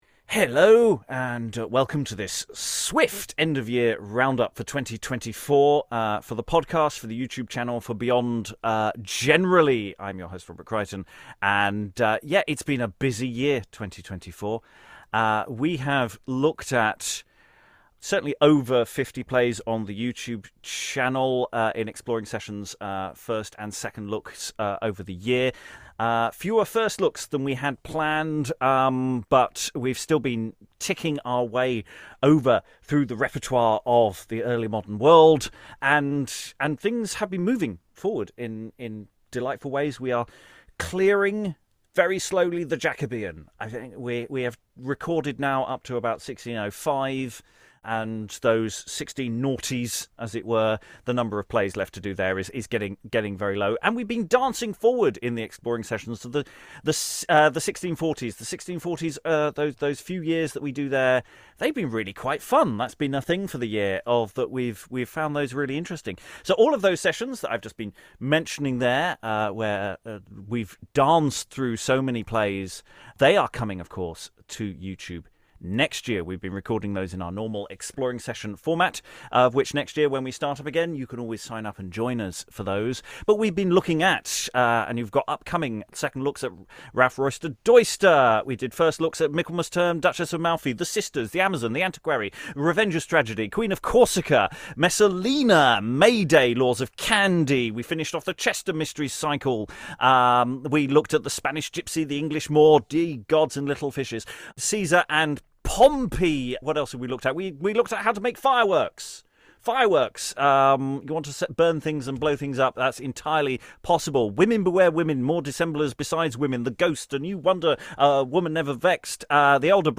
It's my little round up of 2024, all that we've achieved, and what we plan for next year - including the winning play, and the general winners, from our BIG Vote. Recorded at our exploring sessions Christmas Party - I am a tad breathless, doing it all at pace.